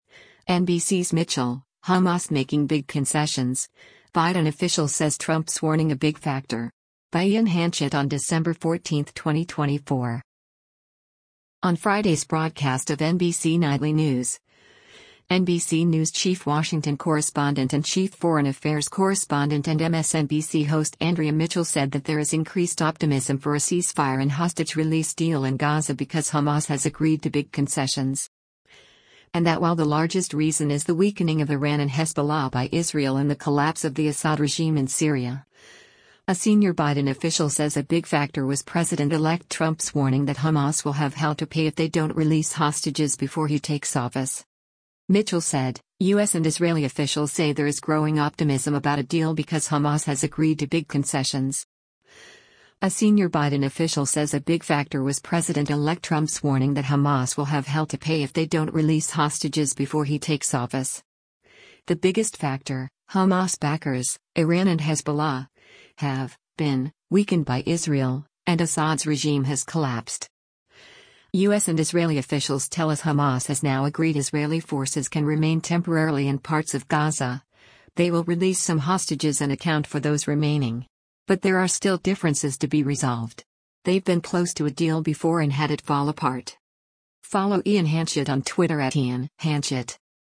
On Friday’s broadcast of “NBC Nightly News,” NBC News Chief Washington Correspondent and Chief Foreign Affairs Correspondent and MSNBC host Andrea Mitchell said that there is increased optimism for a ceasefire and hostage release deal in Gaza “because Hamas has agreed to big concessions.”